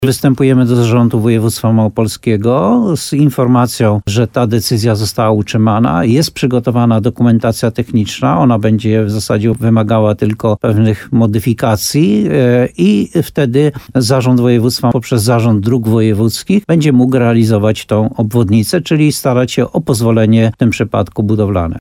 W programie Słowo za Słowo w radiu RDN Nowy Sącz włodarz uzdrowiska przedstawił plan na dalsze działania, jeśli tylko decyzja będzie po myśli samorządu